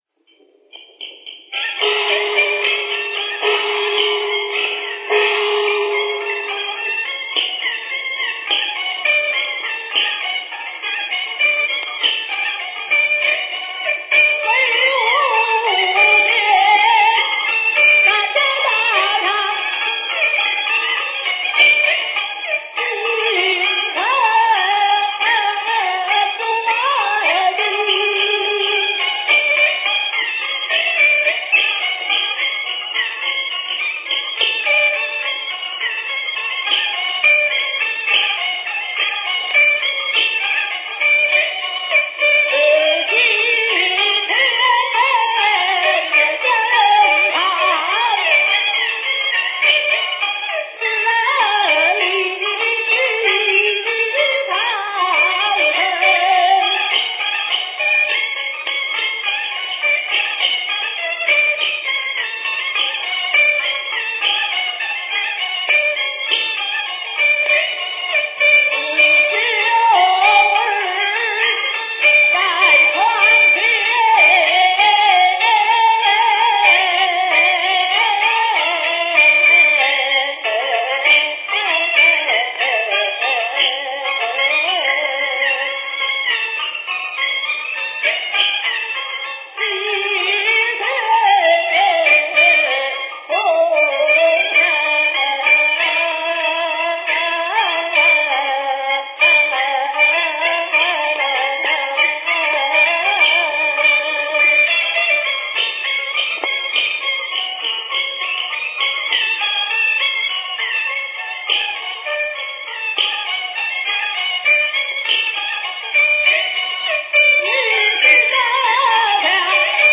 [9/9/2008]我认为是马连良最好听的一段唱